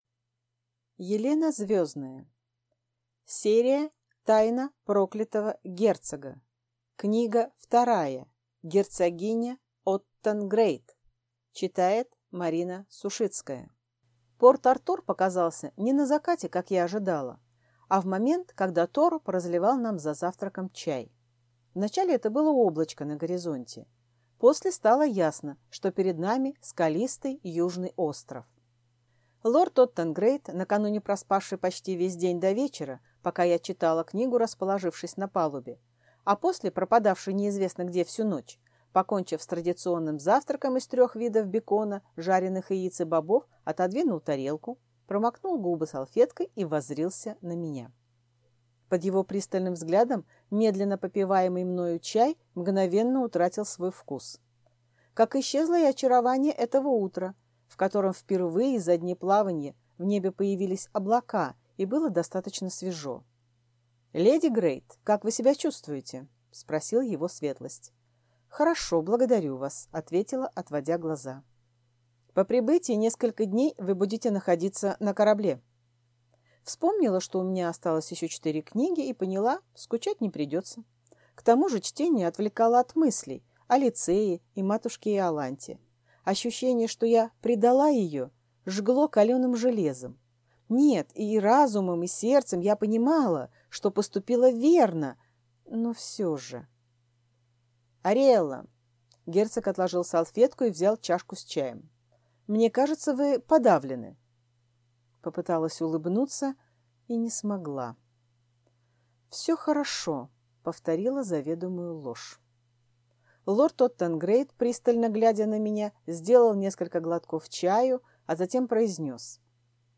Аудиокнига Тайна проклятого герцога. Книга вторая. Герцогиня оттон Грэйд | Библиотека аудиокниг